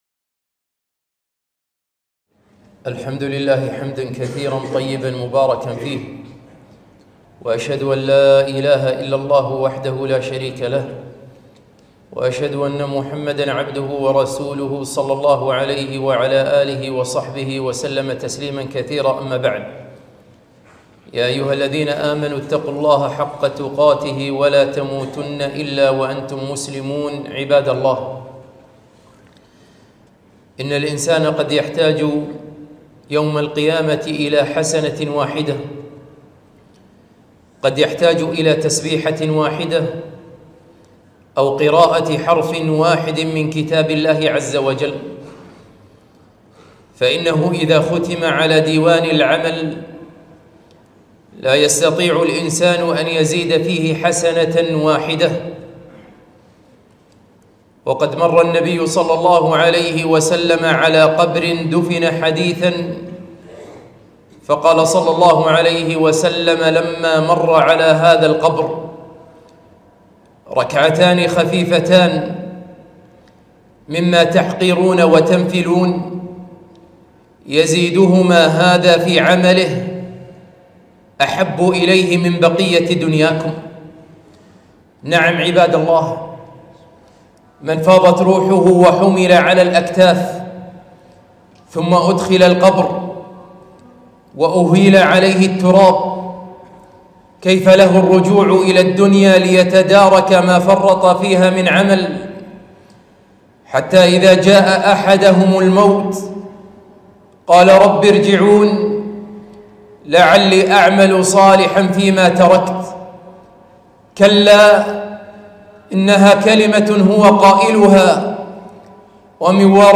خطبة - استغلوا أوقات رمضان